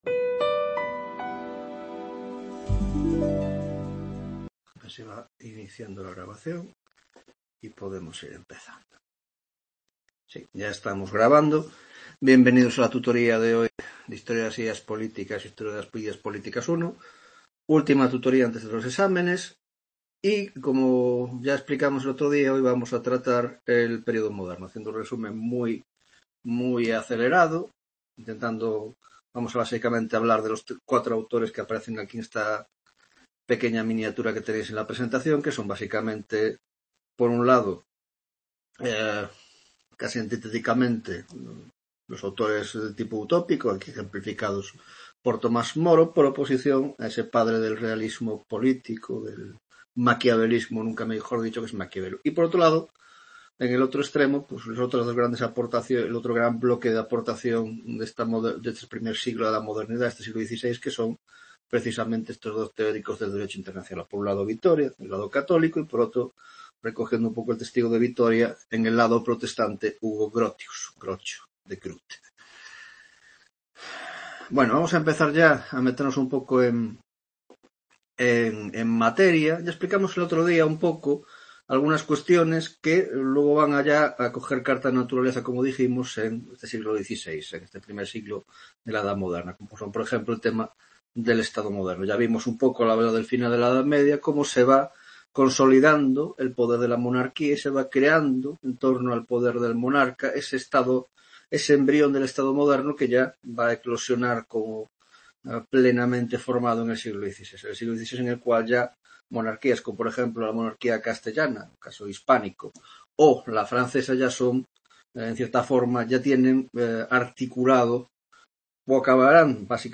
11ª Tutoria de Historia de las Ideas Políticas (Grado de Ciéncias Políticas y Grado de Sociologia) - Pensamiento Político del Renacimiento: 1) Contexto Cultural, Político e Histórico del s. XVI; 2) Pensamiento Utópico (Moro, Campanella y Bacon)